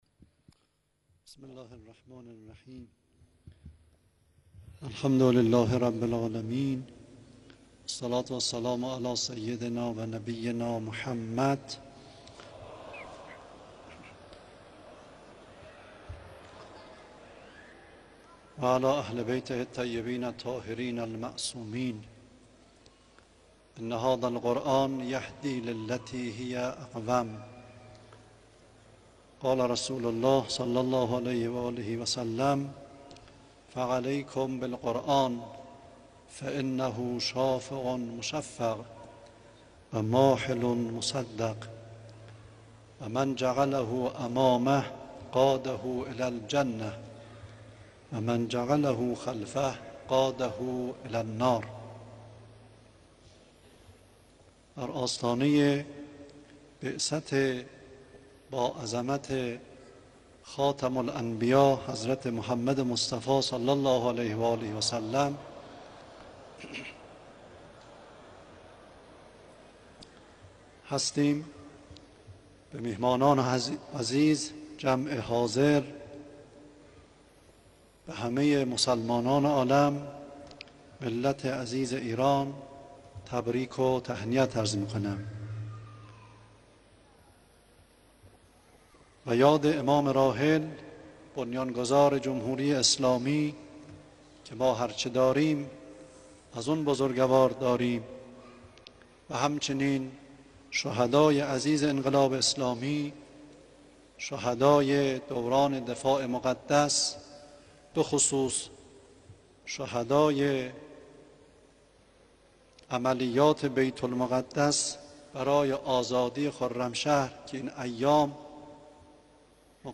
گروه فعالیت‌های قرآنی: نماینده ولی فقیه و رئیس سازمان اوقاف و امور خیریه در آئین افتتاحیه مسابقات بین‌المللی قرآن اظهار کرد: برگزاری همزمان دو رشته حفظ و قرائت قرآن در مسابقات بین‌المللی ایران، منحصر به فرد است.